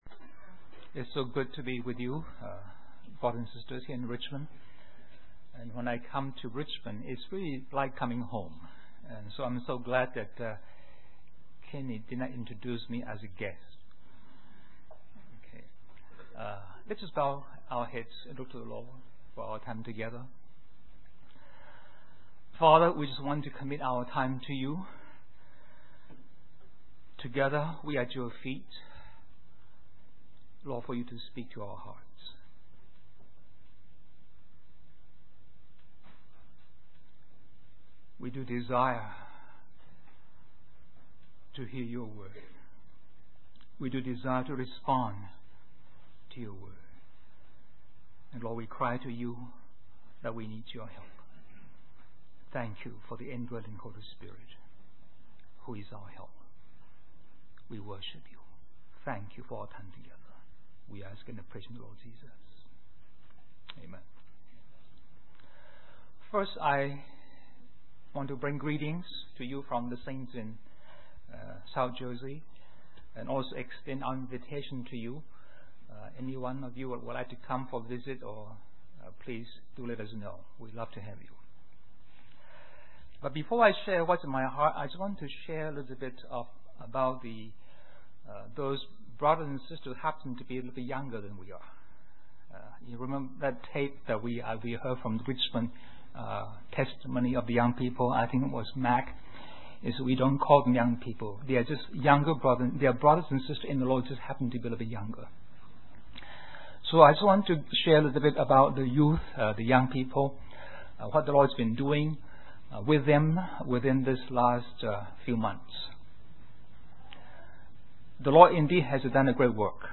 In this sermon, the speaker begins by sharing a personal experience of a young brother leading the worship and setting a powerful tone for the gathering. The speaker emphasizes the importance of young people in the body of Christ and encourages them not to underestimate their role.